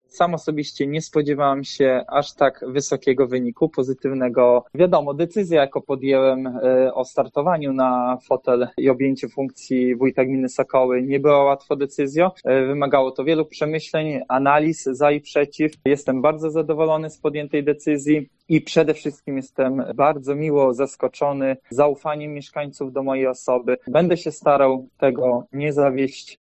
Gdy zobaczyłem wyniki nie dowierzałem-mówi Radosław Choiński: